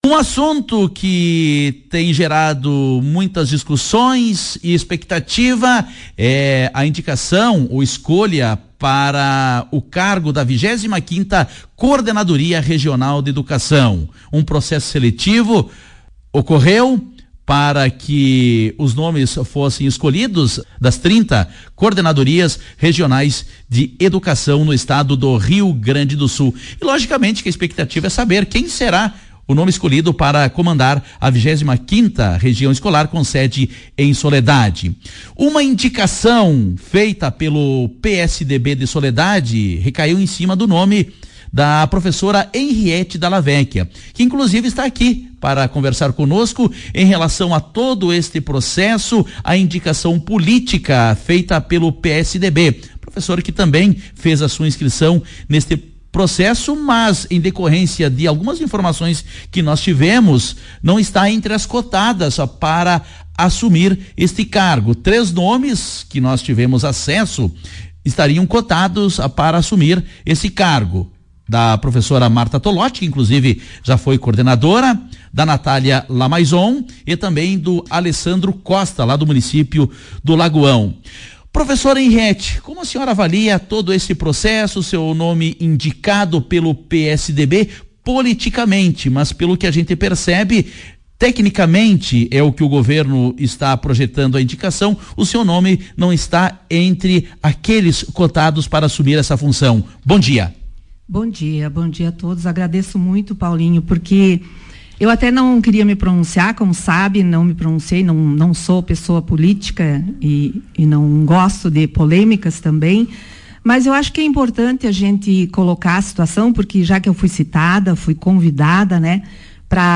Professora destacou, em entrevista, que não existe nada contra em relação aos nomes cotados para o cargo